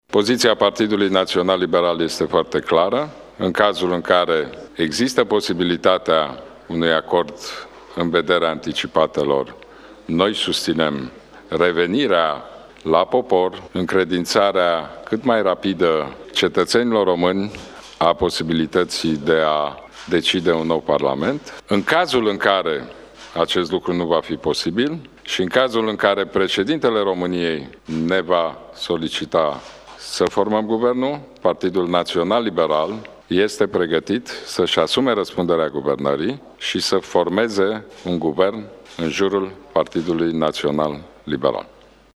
Înainte de USR, preşedintele Iohannis a avut consultări cu delegaţia PNL. Președintele Ludovic Orban a declarat că PNL este pregătită să intre la guvernare:
stiri-11-oct-declaratii-PNL-1.mp3